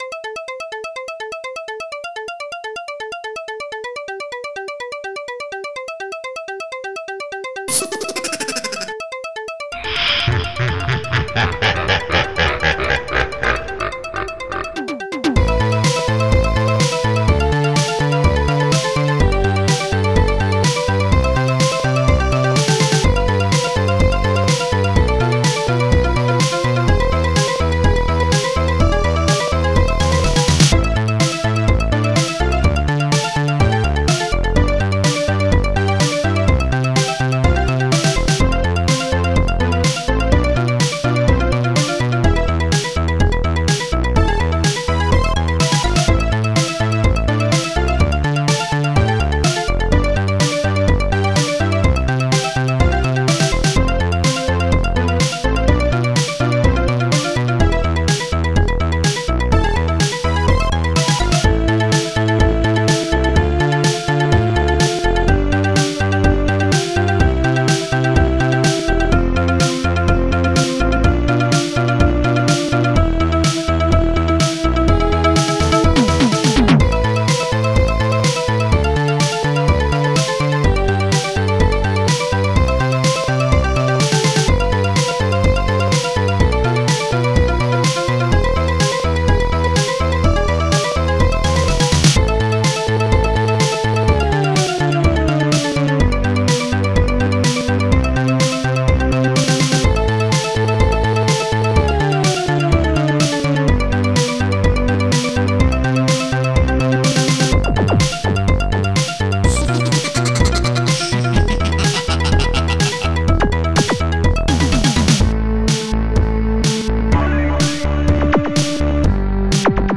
Protracker and family
st-02:bassdrum5
st-01:polysynth
st-01:popsnare2
st-01:strings1